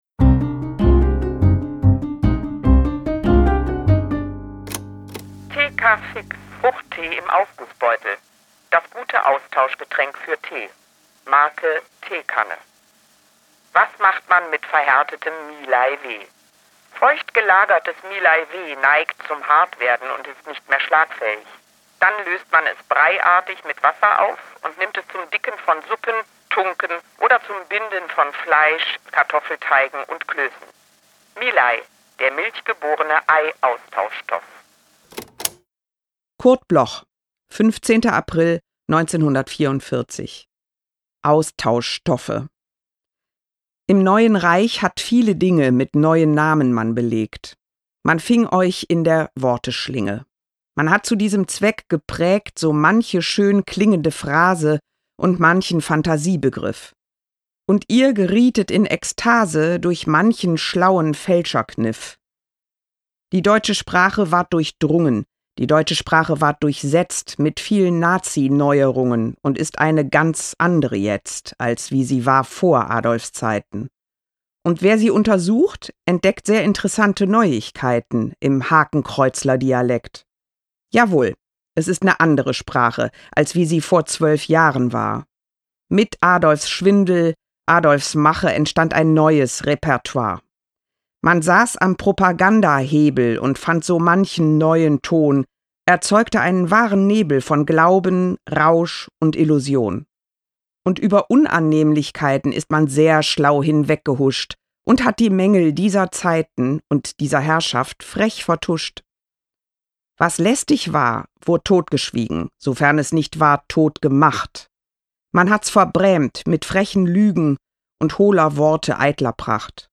vorgetragen von Cordula Stratmann
Cordula-Stratmann-Austauschstoffe-mit-Musik.m4a